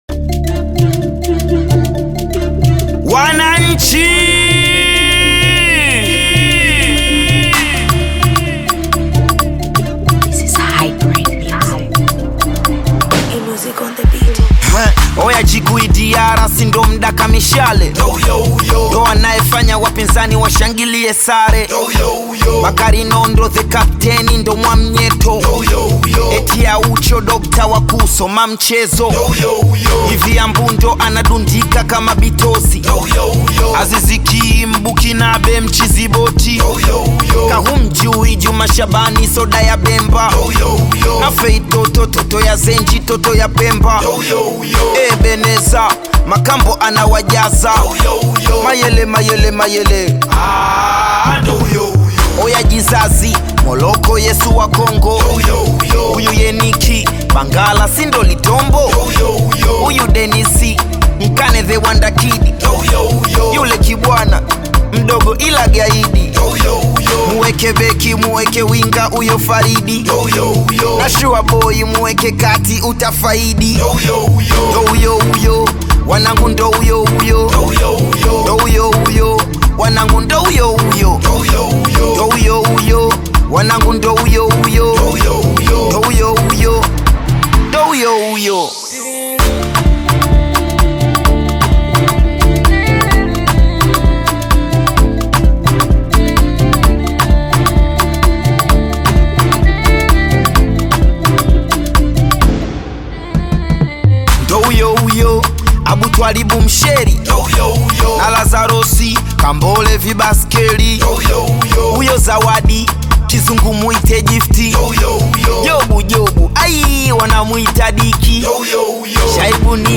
Tanzanian bongo flava
African Music